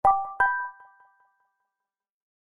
S_Charming_bell.mp3